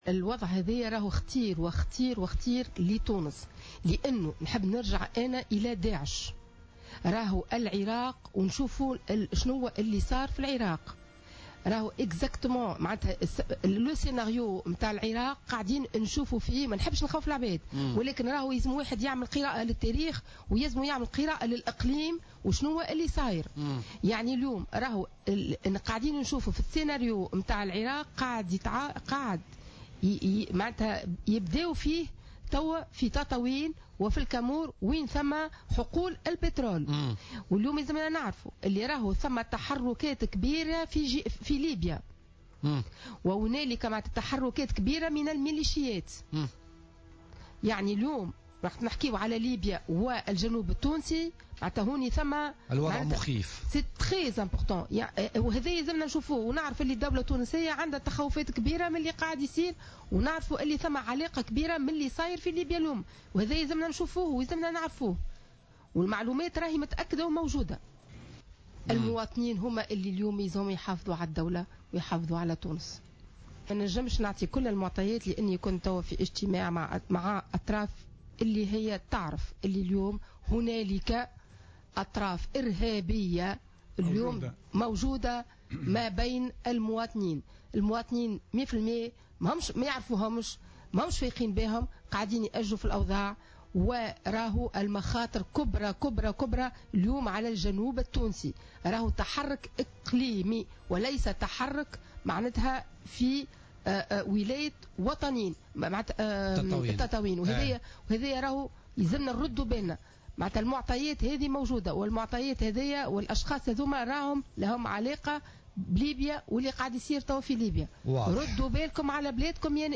وأضافت الشتاوي خلال استضافتها اليوم في "بوليتيكا"، أن التحرك الإقليمي في الجنوب، وخاصة تطاوين على علاقة مباشرة بليبيا وما يحدث بها الآن، محذّرة من تكرار سيناريو "داعش" في العراق بتطاوين أين توجد حقول البترول.